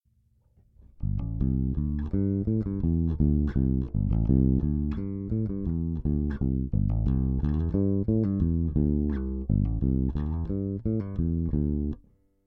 In this example, I play the same riff with a clean technique, and then with the addition of slides, hammers, and pull offs to add interest.
Bass hammer Pull And Slide Riff Example
bt4_8_Electric_Bass_Hammer_Pull_And_Slide_Riff.mp3